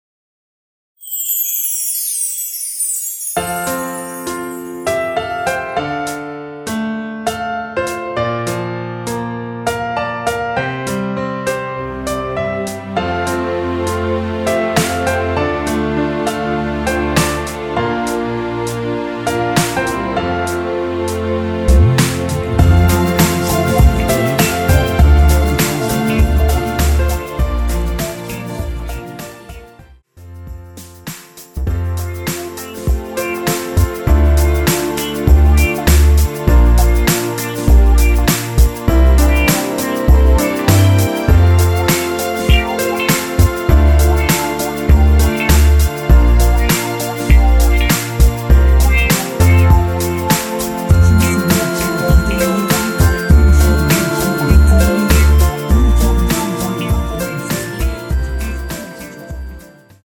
랩과 애드립 등은 없으며 미리듣기의 코러스만 있습니다.(미리듣기 참조)
앞부분30초, 뒷부분30초씩 편집해서 올려 드리고 있습니다.
중간에 음이 끈어지고 다시 나오는 이유는